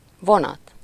Ääntäminen
IPA: [lə tʁɛ̃]